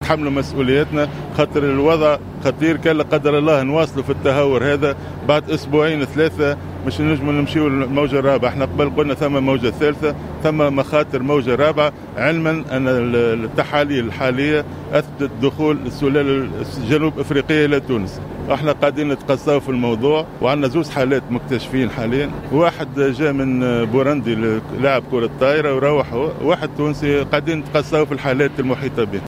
وفي تصريح نقلته الوطنية أفاد وزير الصحة بان الاصابة الأولى لشخص من بوروندي وهو لاعب كرة طائرة وقد عاد الى بلده والثاني يحمل الجنسية التونسية وبصدد تقصي الحالات المحيطة به.